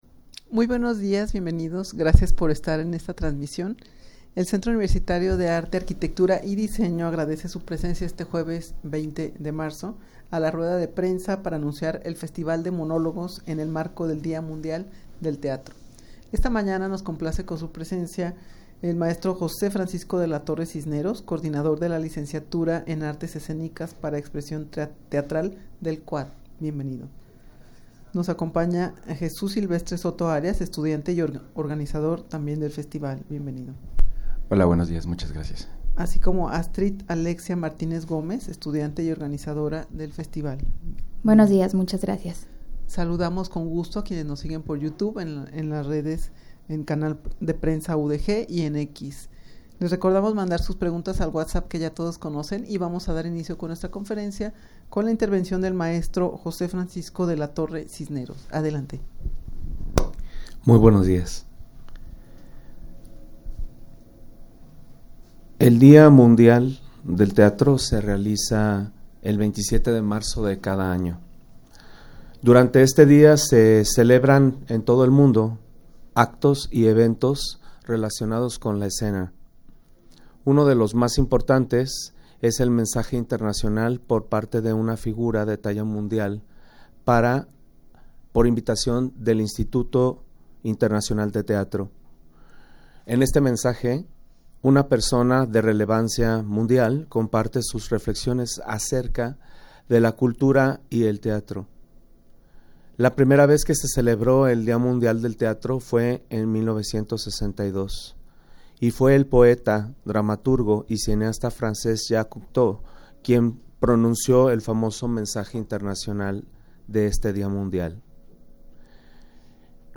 rueda-de-prensa-para-anunciar-el-festival-de-monologos-en-el-marco-del-dia-mundial-del-teatro.mp3